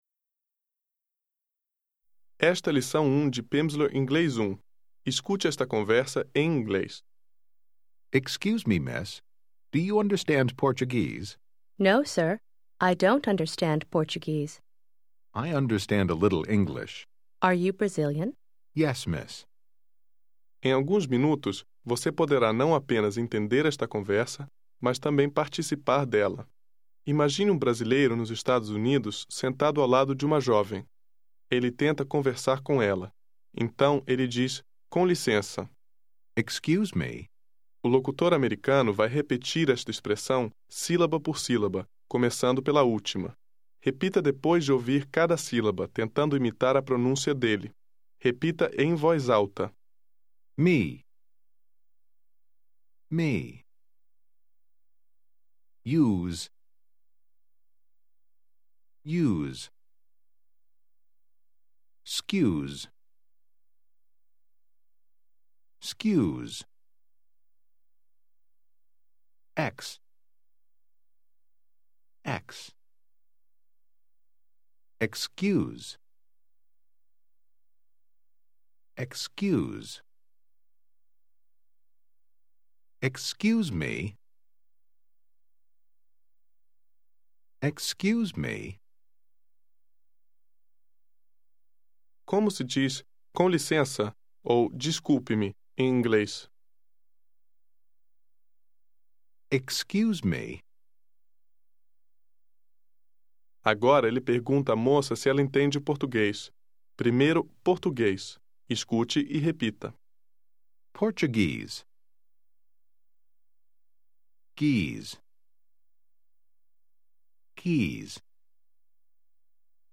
English for Brazilian Portuguese Speakers Phase 1, Unit 1 contains 30 minutes of spoken language practice, with an introductory conversation, and isolated vocabulary and structures.